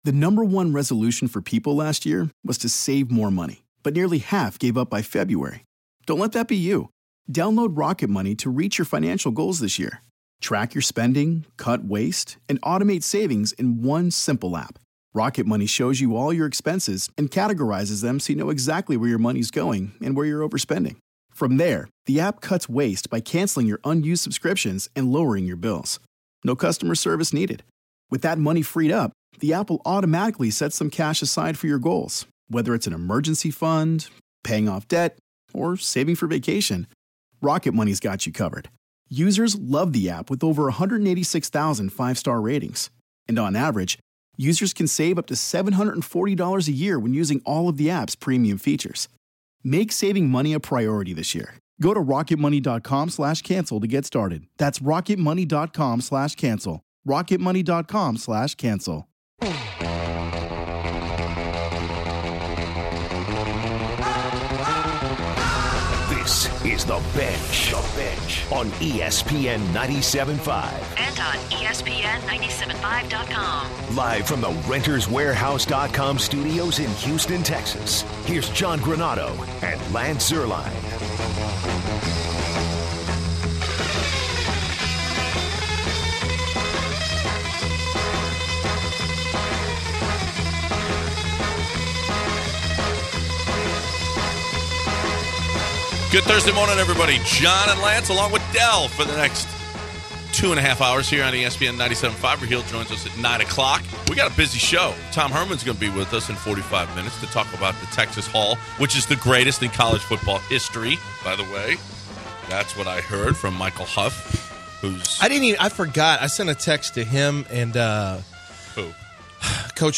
To finish out the hour, University of Texas Head Coach Tom Herman joins The Bench to discuss his brand new recruiting class, the impact of the newly implemented early signing period, his team’s preparation for their bowl game, and more.